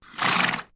Whinnies
Horse Whinnies & Blows 6